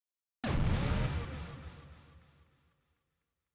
ufo_destroy_002.wav